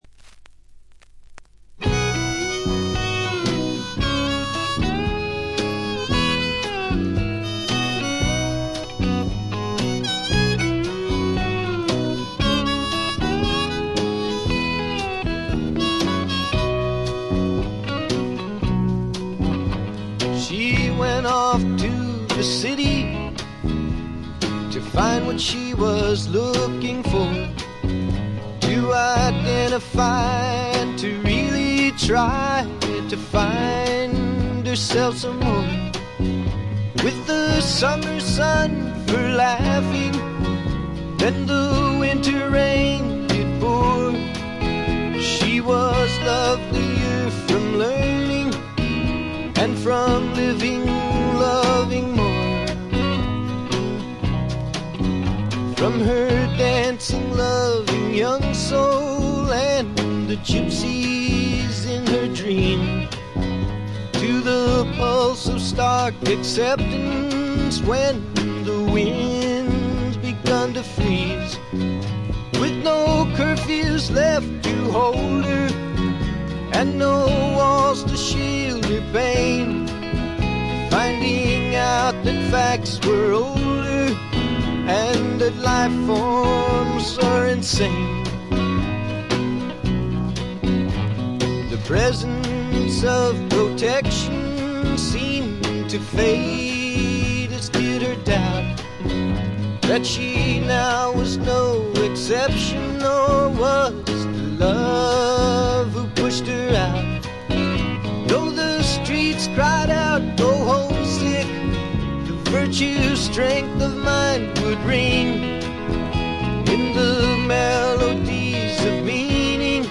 これ以外は軽微なバックグラウンドノイズにチリプチ少々。
試聴曲は現品からの取り込み音源です。
Recorded at The Village Recorder